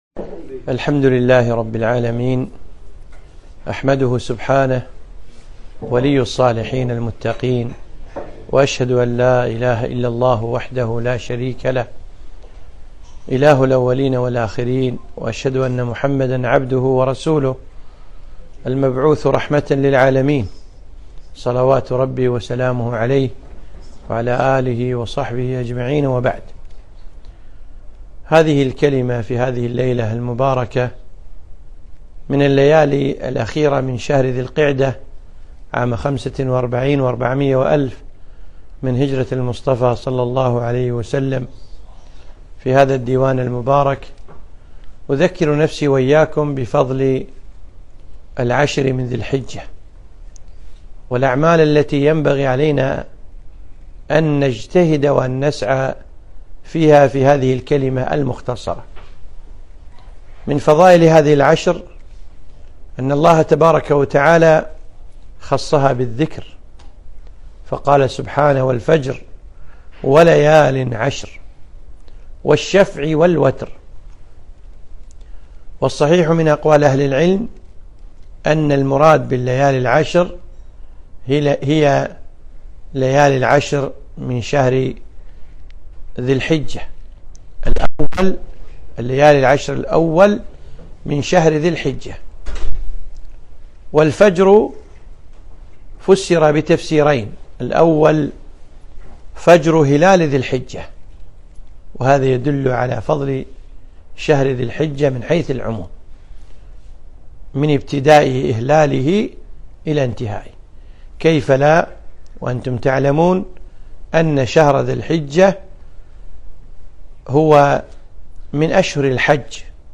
كلمة - فضل العشر من ذي الحجة